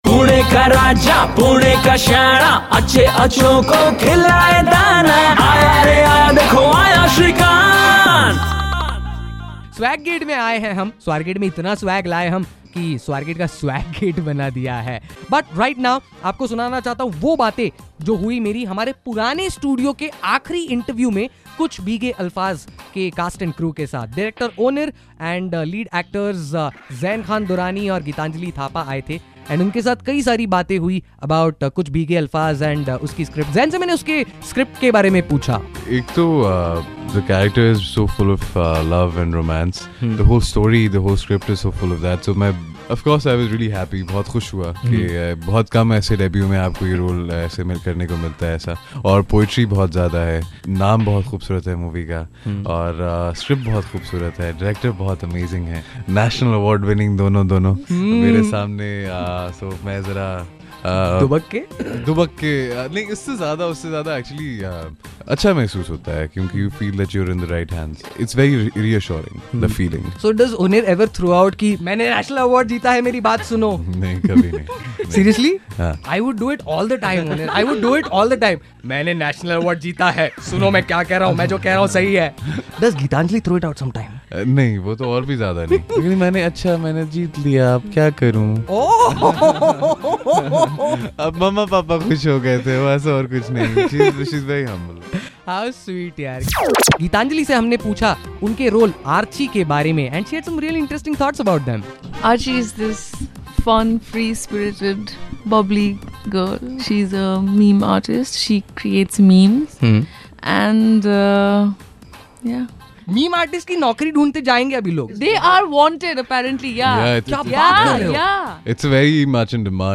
IN THE RED FM STUDIO'S